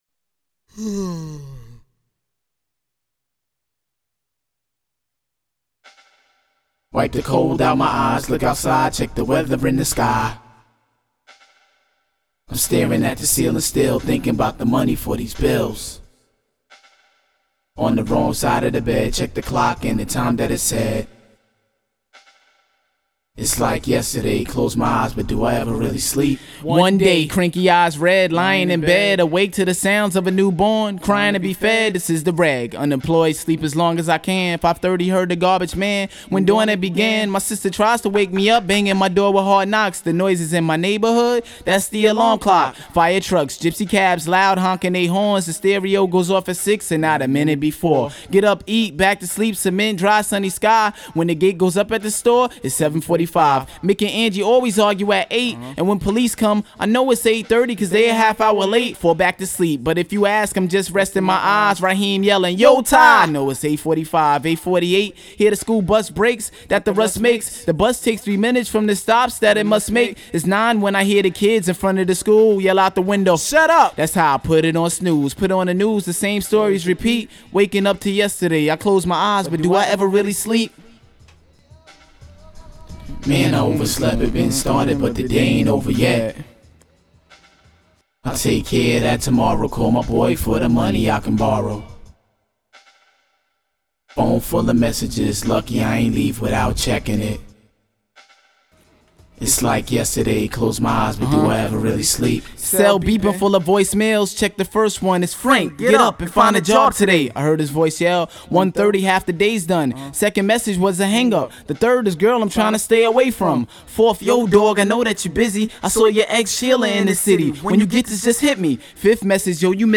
in acapellas